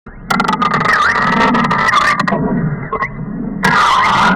A segment of the "Glass Break 1" audio file, with several audio filters and effects placed on it. This sound is correlated with the letter "s" on the computer keyboard.